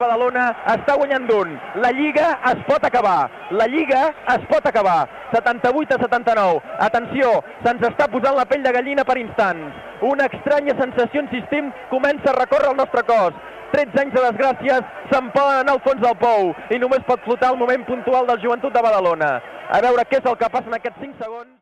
Transmissió dels últims segons del partit del "play-off" pel títol de la Lliga ACB, de bàsquet masculí, entre el Montigalà Joventut i el F.C. Barcelona.
Esportiu
FM